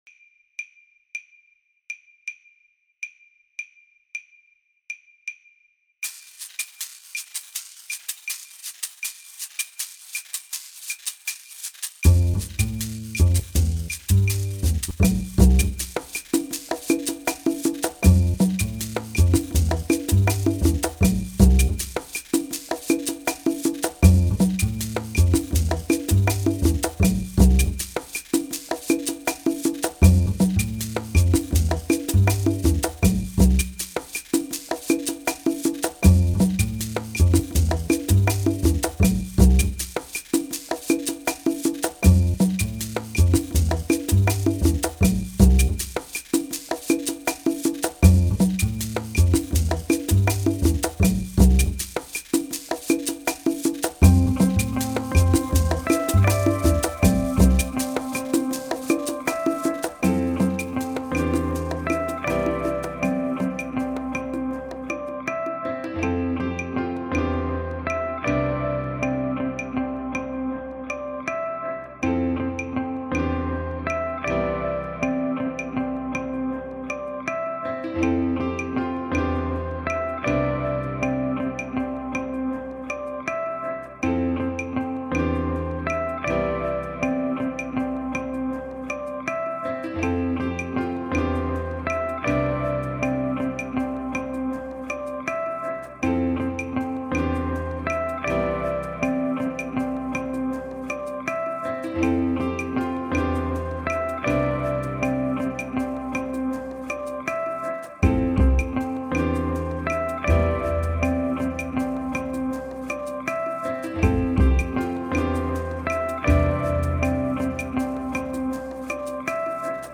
Latin Improv Track in C (loop)